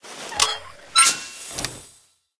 flamerReload.ogg